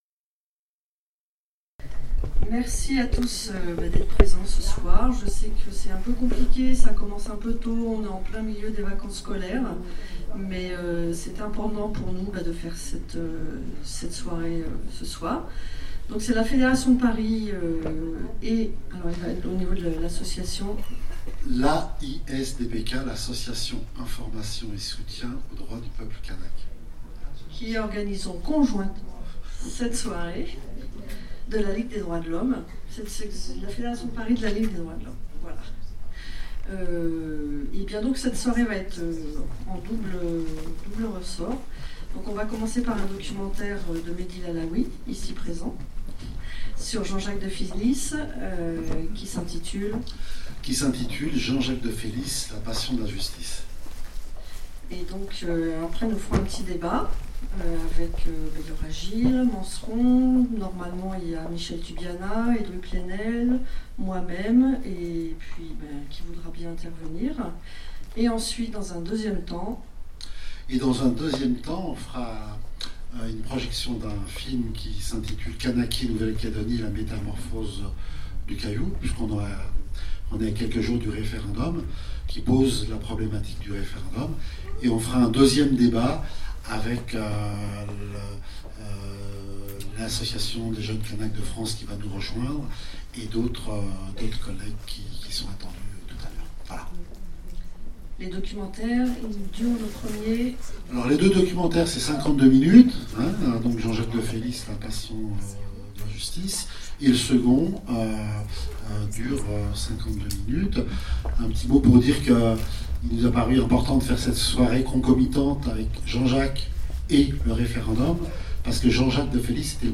Trente ans après le drame d’Ouvéa et les accords de Matignon, sous quelle forme la décolonisation de ce territoire va-t-elle se poursuivre ? Une soirée/débat à Paris pour éclairer cette question.